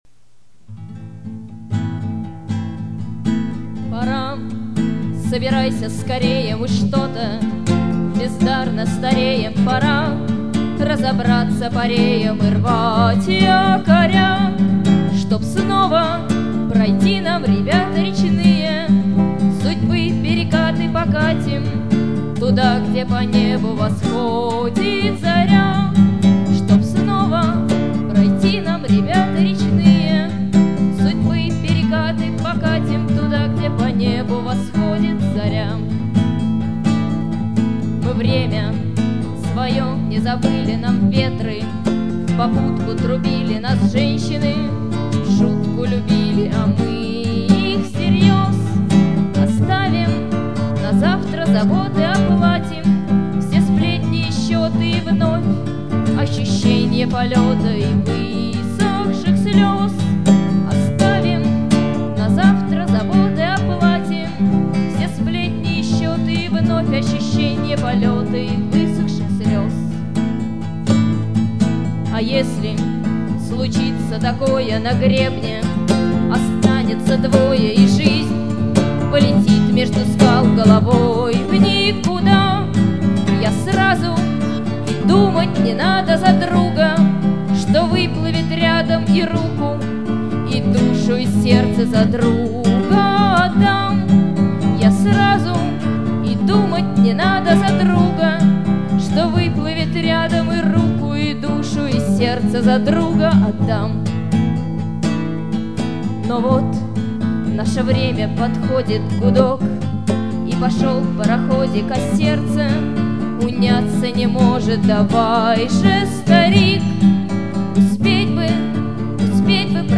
• Исполняет: Сигнальщики
• Жанр: Авторская песня